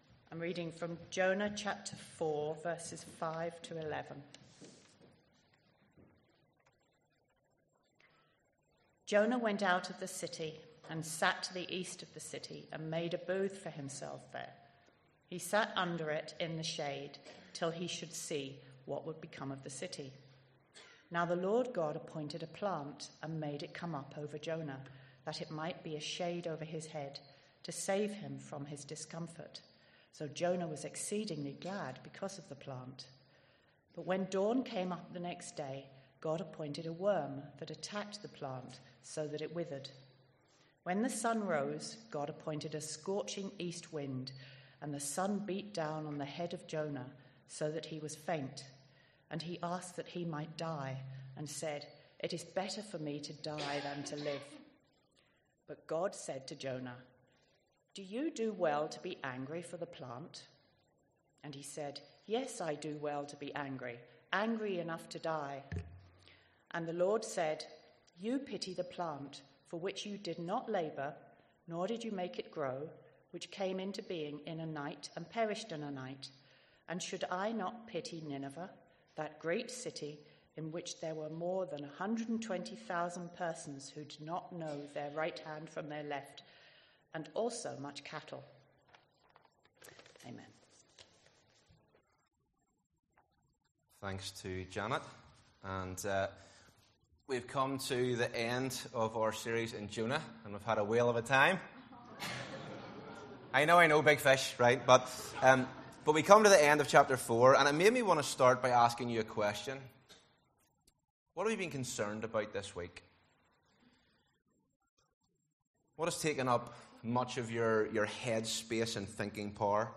Sermons based on Jonah